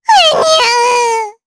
Estelle-Vox_Damage_jp_8.wav